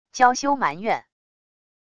娇羞埋怨wav音频